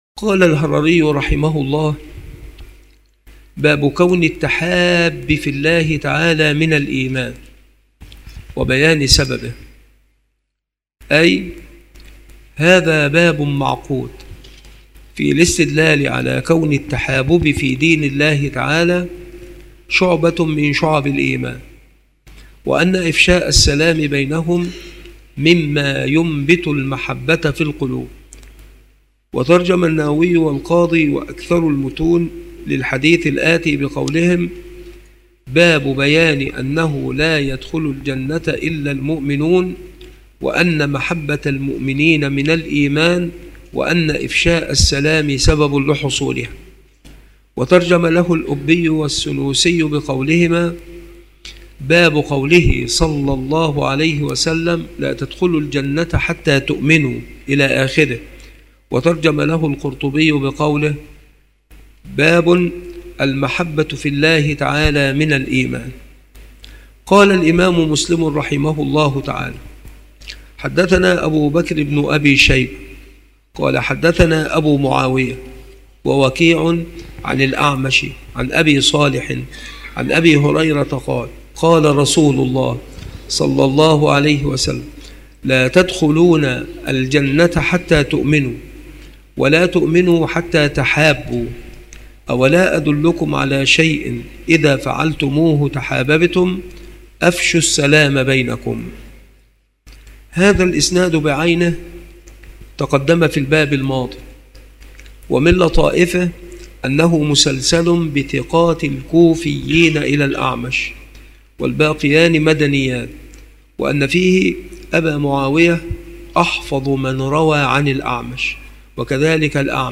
شروح الحديث
مكان إلقاء هذه المحاضرة بالمسجد الشرقي بسبك الأحد - أشمون - محافظة المنوفية - مصر